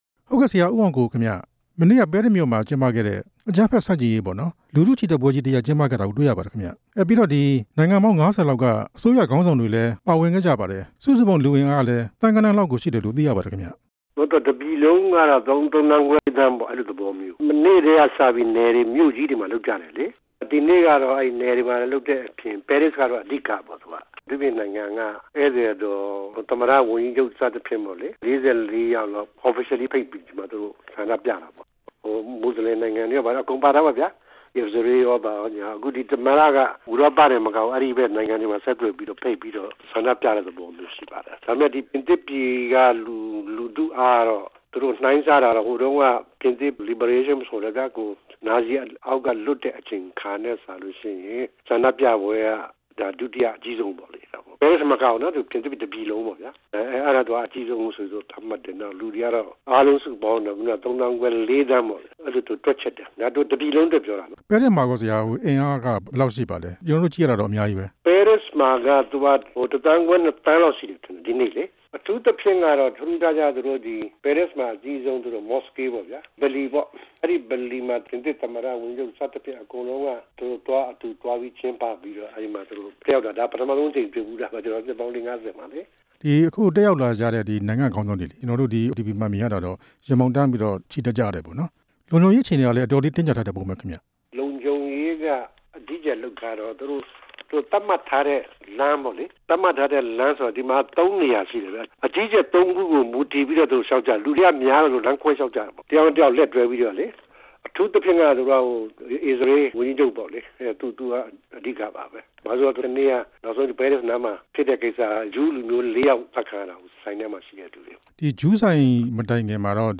ပြင်သစ်နိုင်ငံက အကြမ်းဖက် တိုက်ခိုက်မှု အကြောင်း မေးမြန်း ချက်